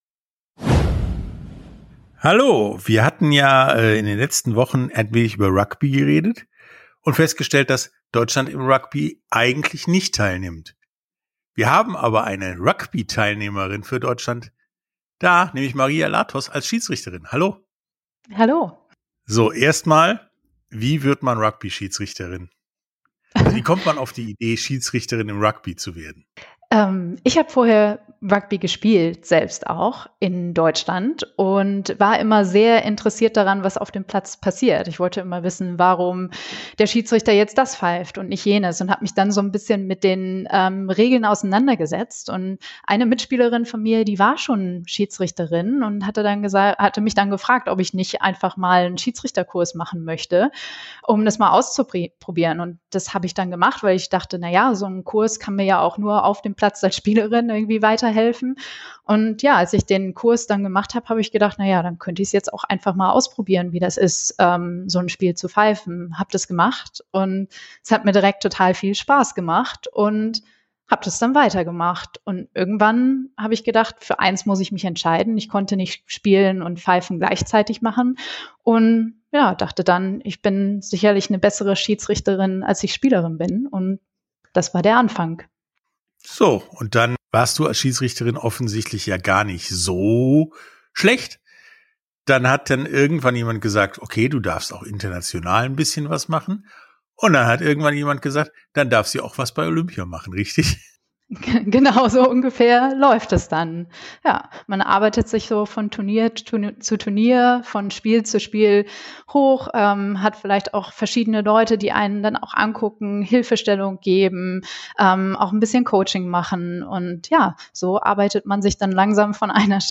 Sportstunde - Interviews in voller Länge Podcast